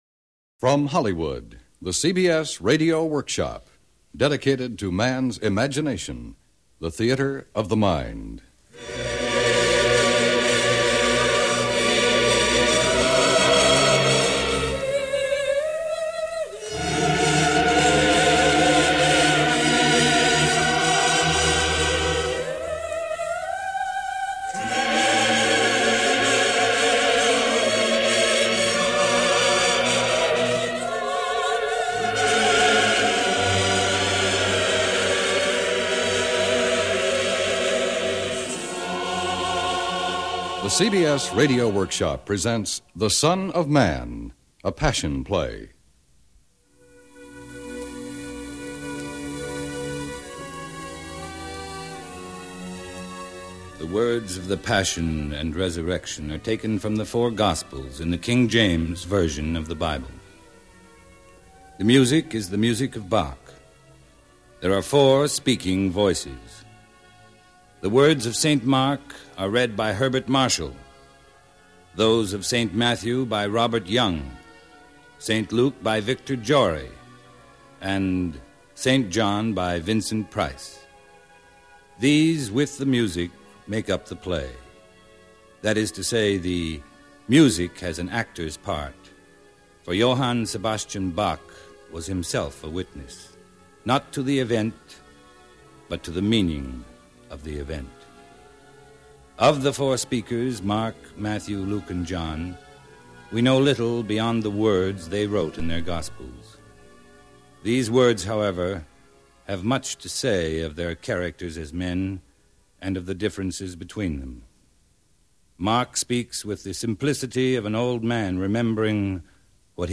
CBS Radio Workshop with host and narrator William Conrad